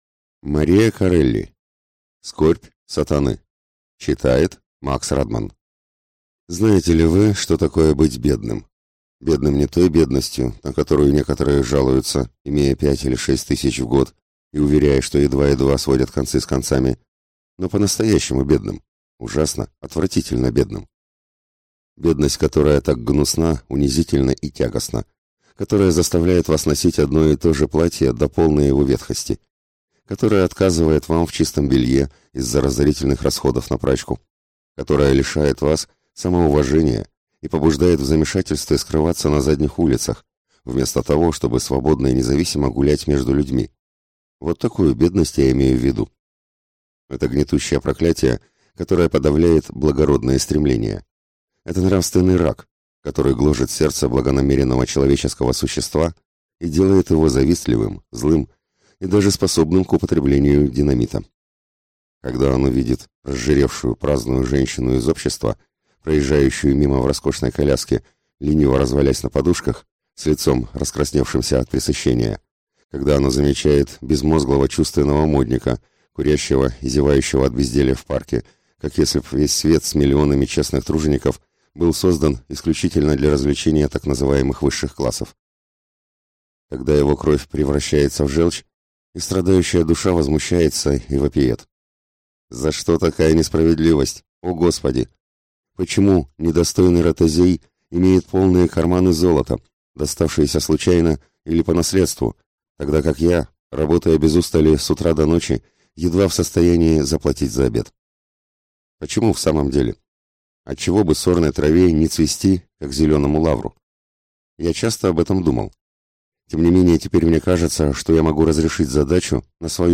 Аудиокнига Скорбь Сатаны | Библиотека аудиокниг